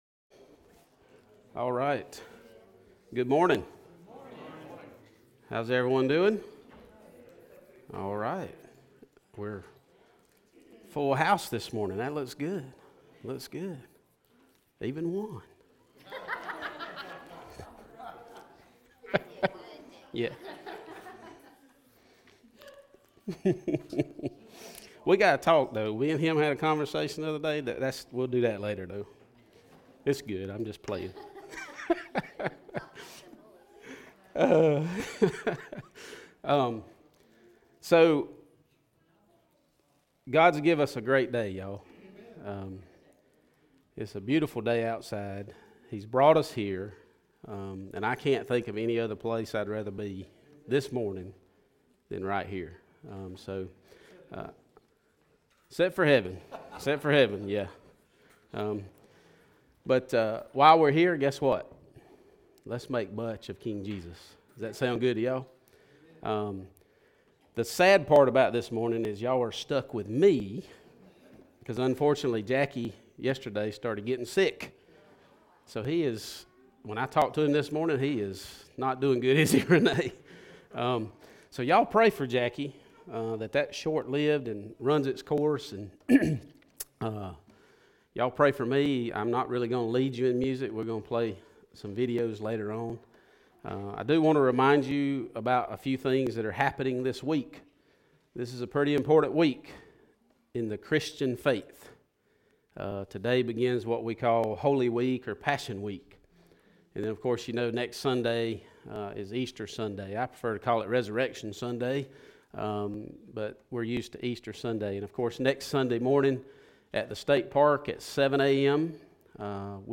Message Type - Sermon
Occasion - Sunday Worship